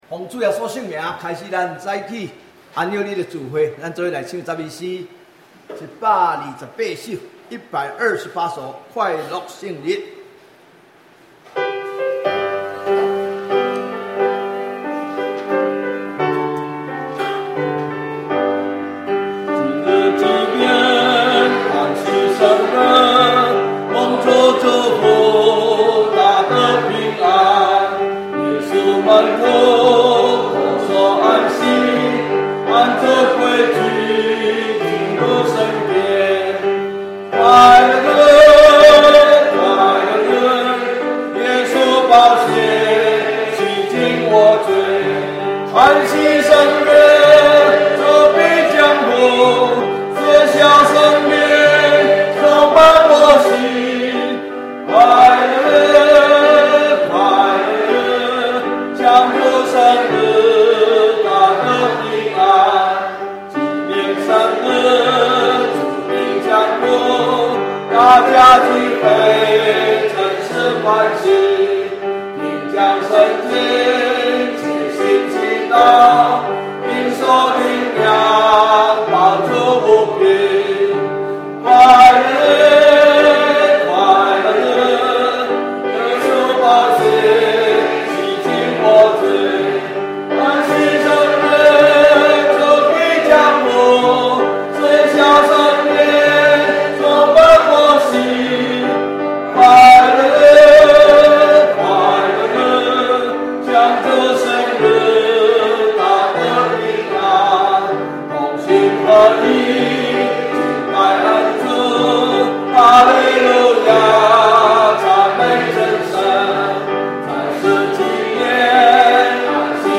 2016年幸福婚姻週專題講道